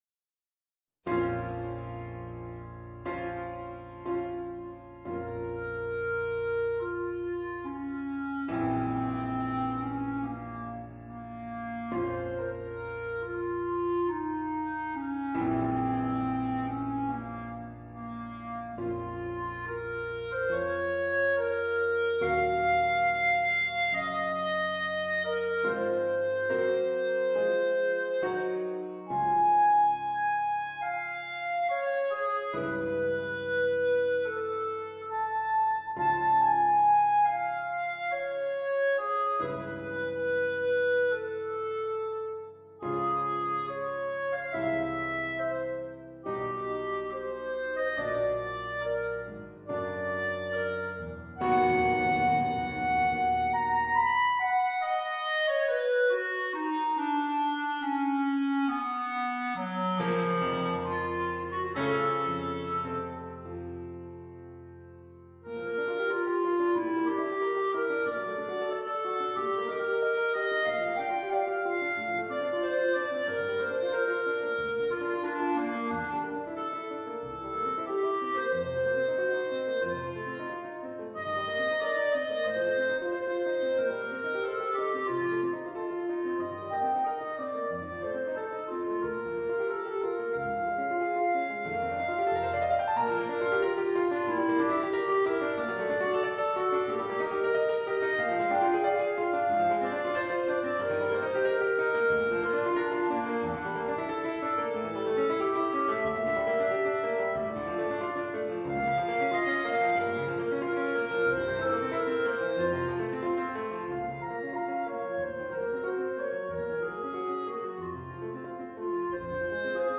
per clarinetto e pianoforte